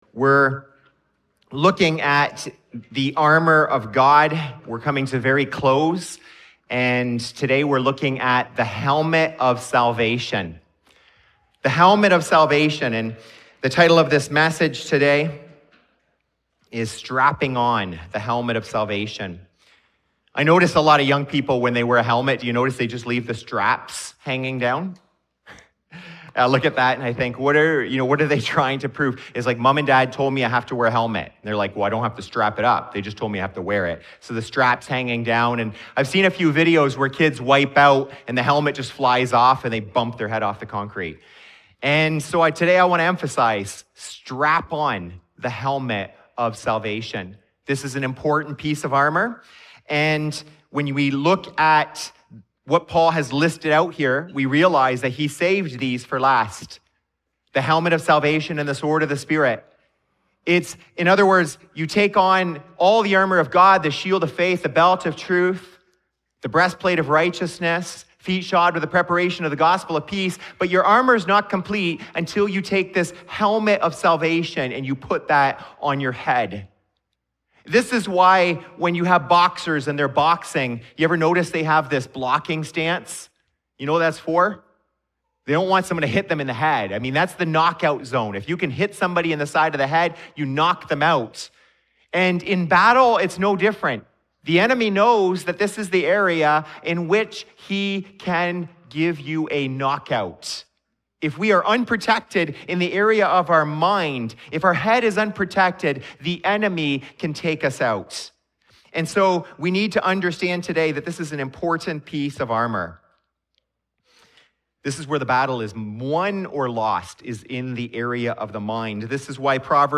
From Romans 8, we see how this helmet renews your mind, reframes your suffering, and restores your confidence. You’ll walk away from this sermon knowing how to think like someone who’s been saved, not just say you are.